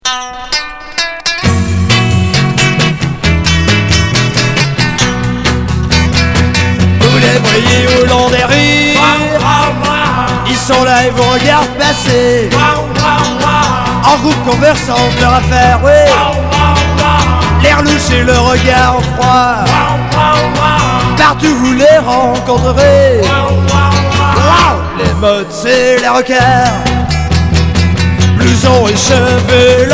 Merseybeat